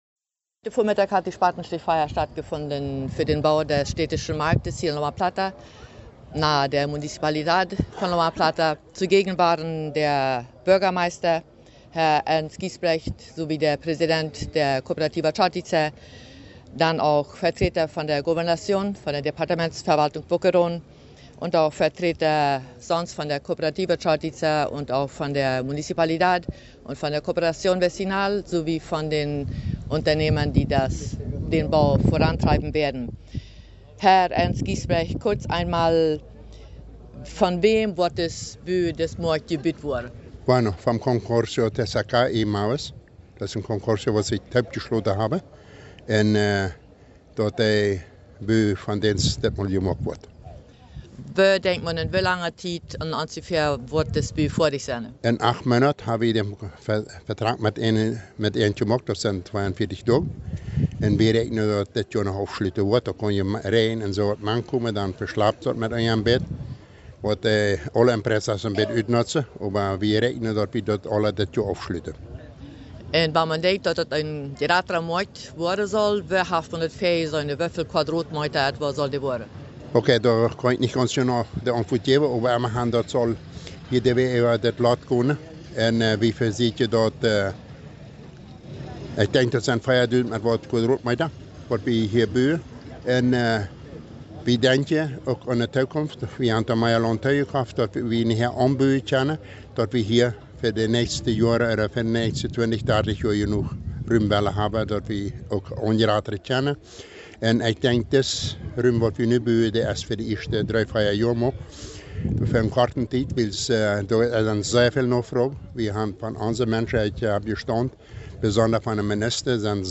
IV zur Spatenstichfeier zum "Mercado Municipal"